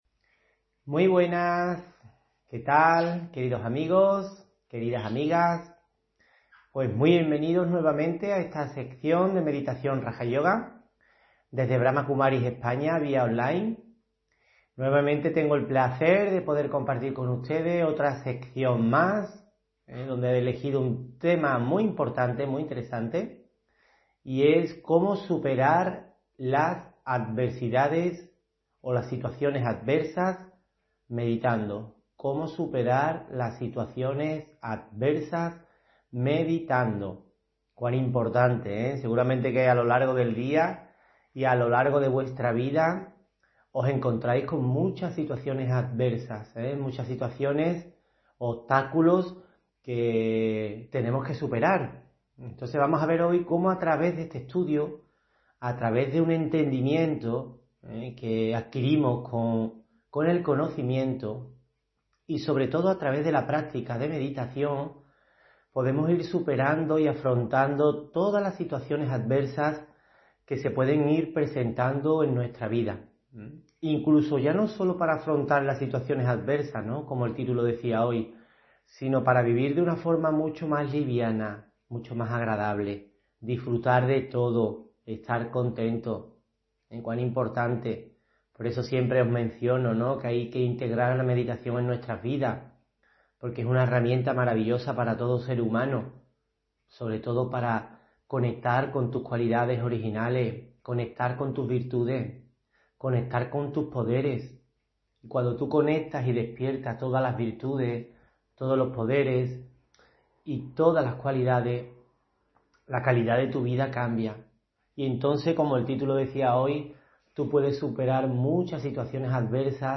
Meditación y conferencia: Cómo superar las situaciones adversas meditando (7 Febrero 2024)